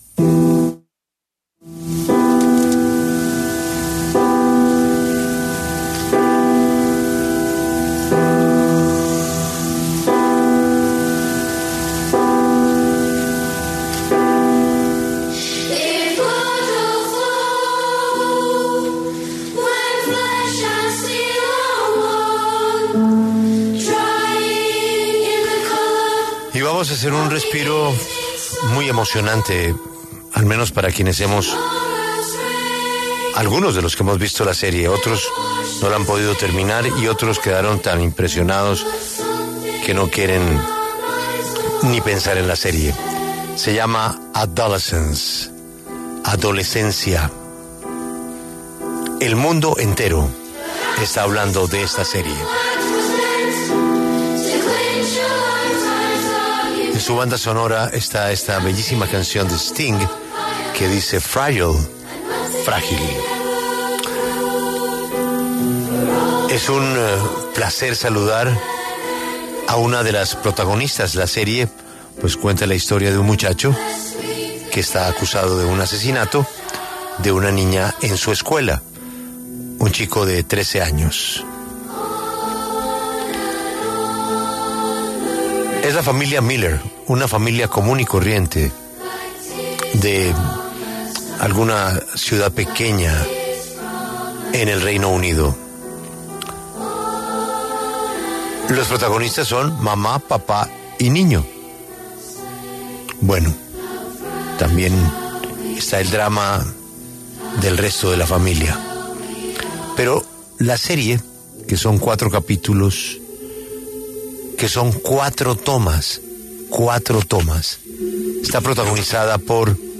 Actriz de Adolescence, Christina Tremarco, en La W